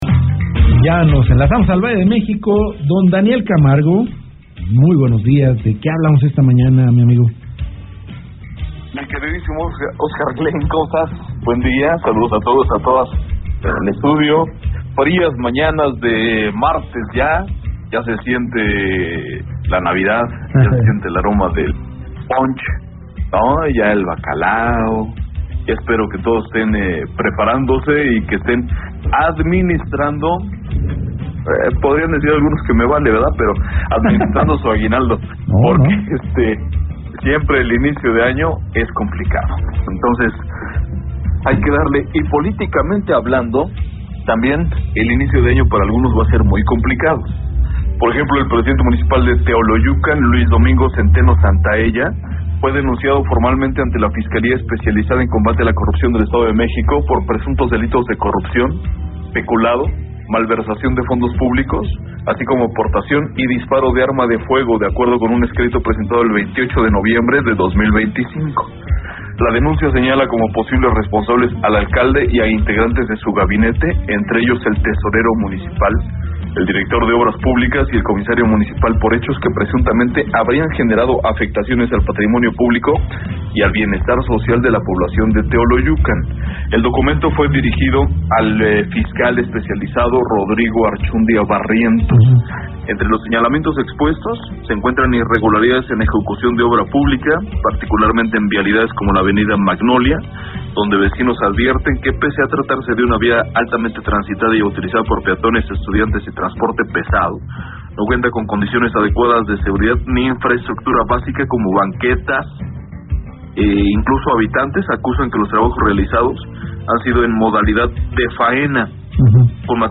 comentarista político